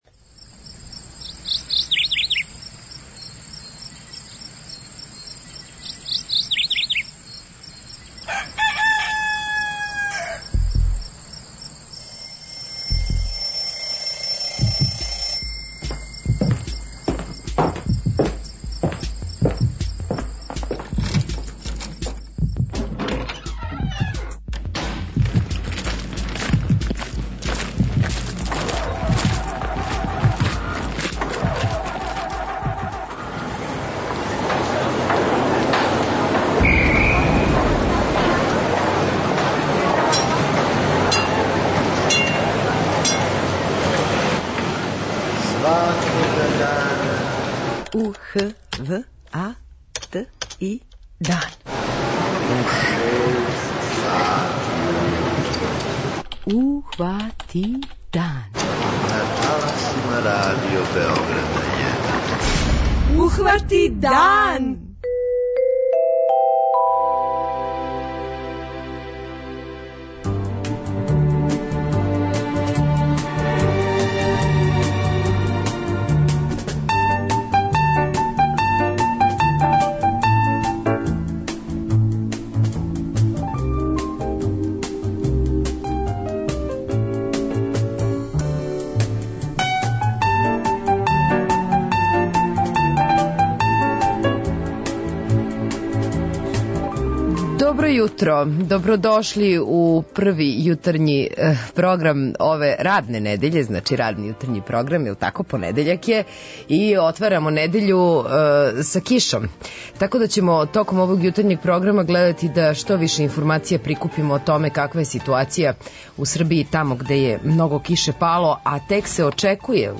преузми : 43.14 MB Ухвати дан Autor: Група аутора Јутарњи програм Радио Београда 1!